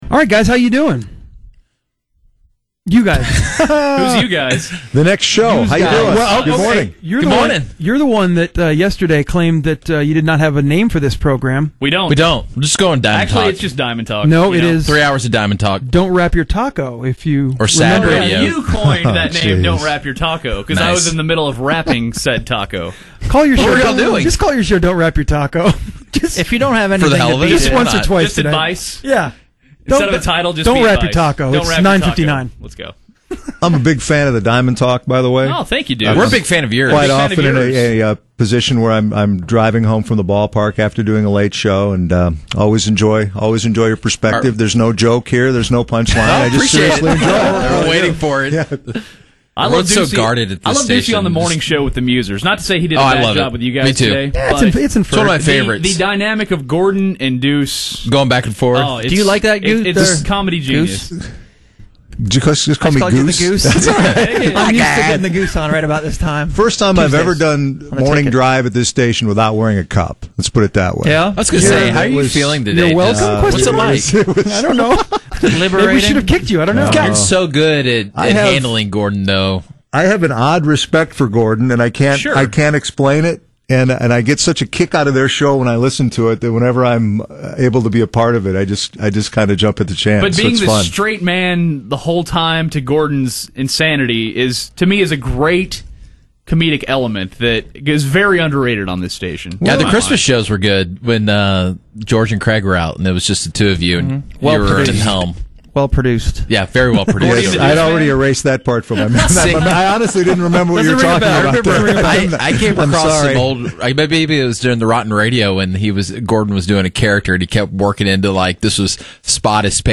impromptu rendition